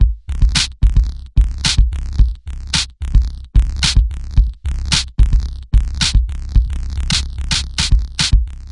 描述：芯片噪声计算机循环
标签： 循环 180 噪声 芯片 计算机环
声道立体声